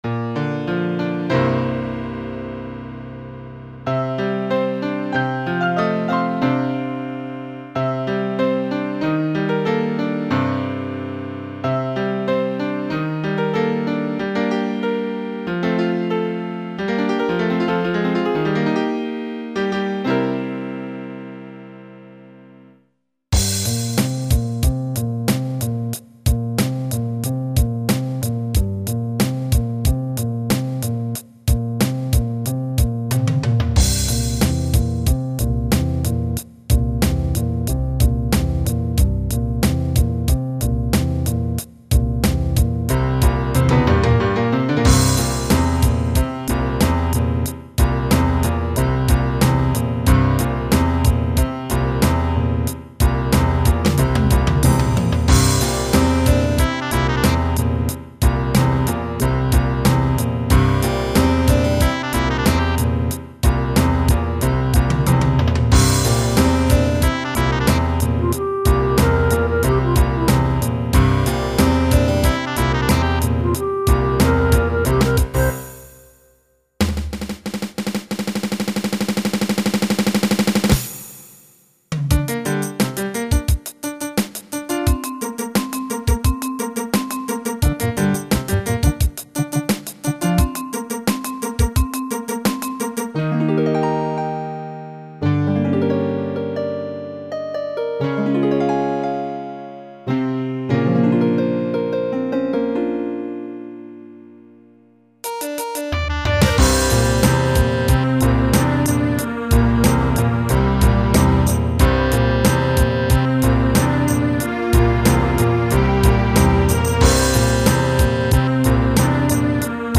FUSION MUSIC